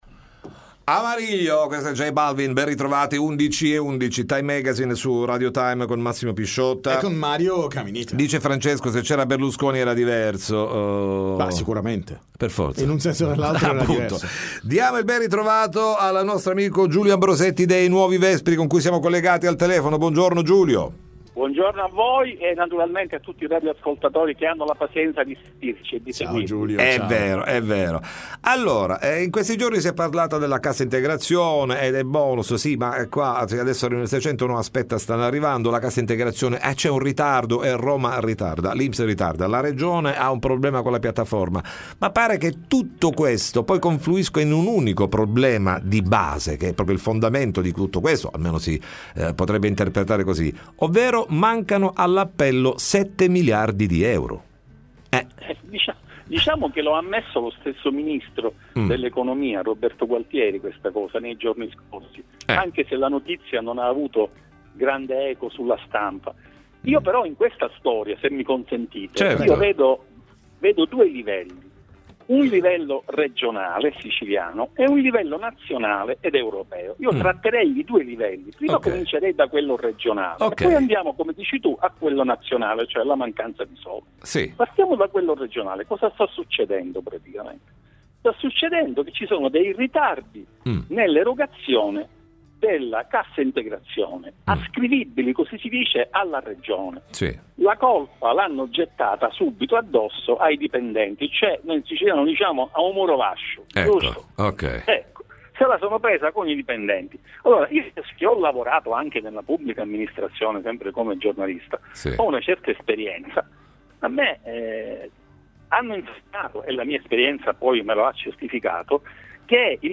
Time Magazine intervista